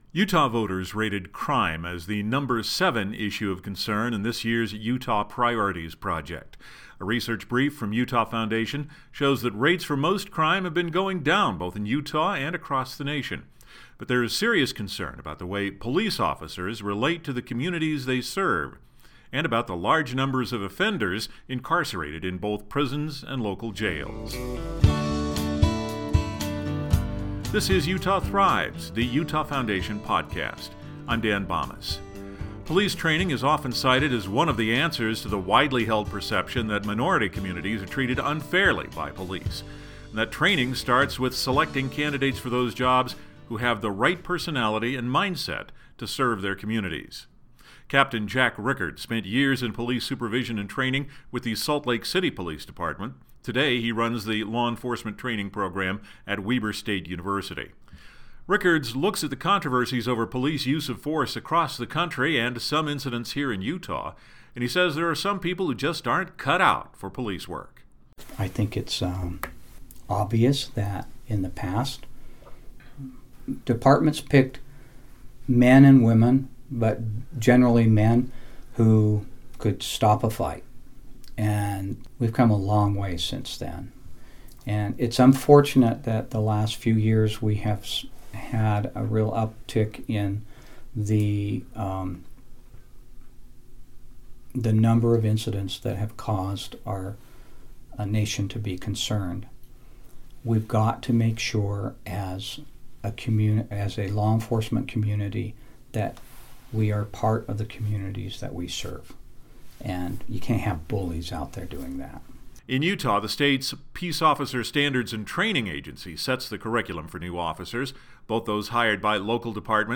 And we’ll hear from Salt Lake County Mayor Ben McAdams, who believes a new Pay for Success program meant to address criminal behavior among repeat offenders in the county jail could help to reduce the costs of booking them into custody time and time again.